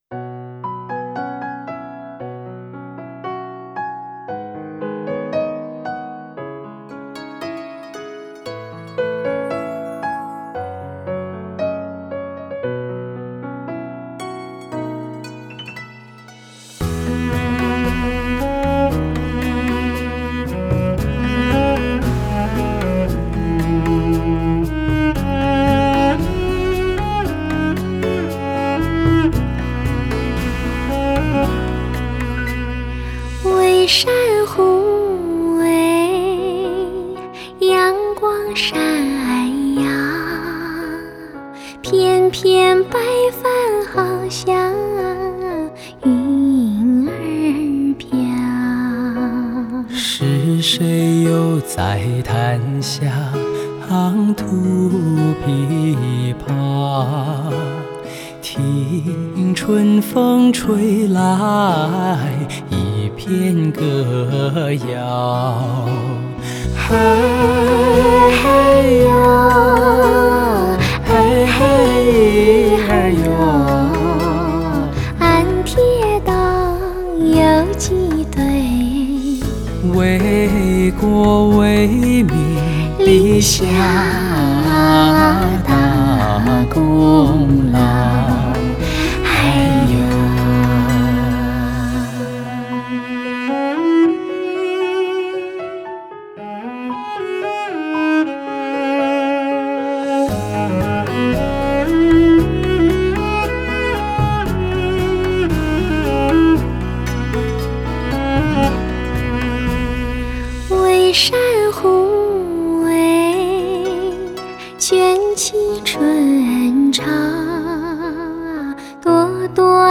可惜这么多好歌被唱得都是一个甜甜的味道